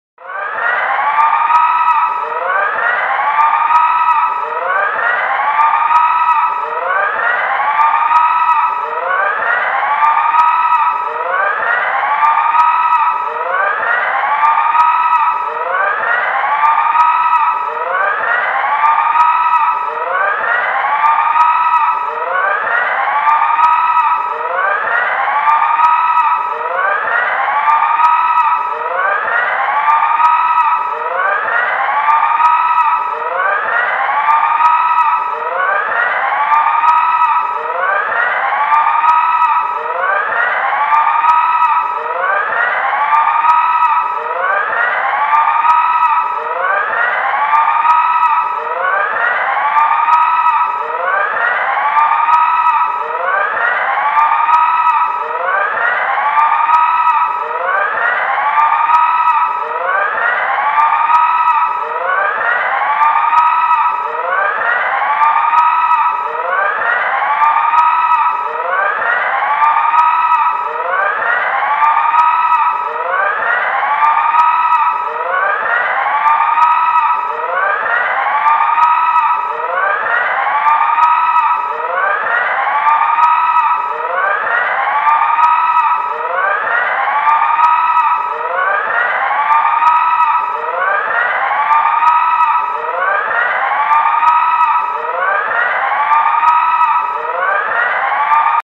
Board警报声.mp3